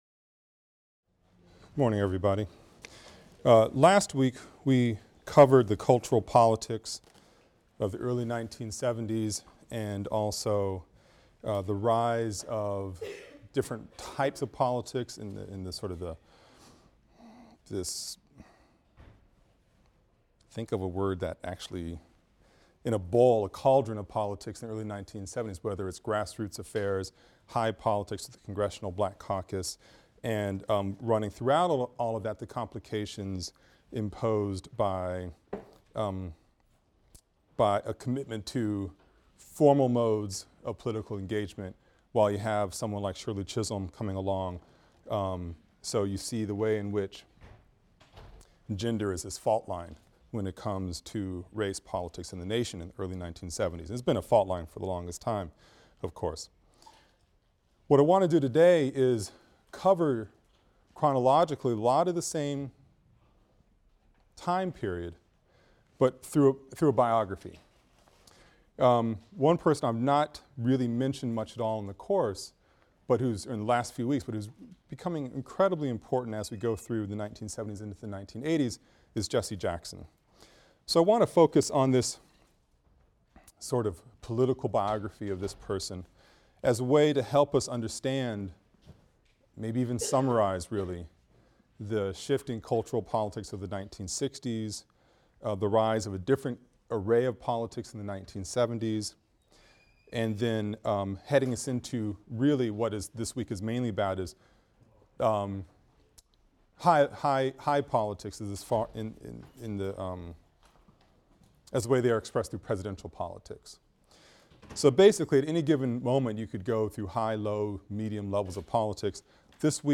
AFAM 162 - Lecture 22 - Public Policy and Presidential Politics | Open Yale Courses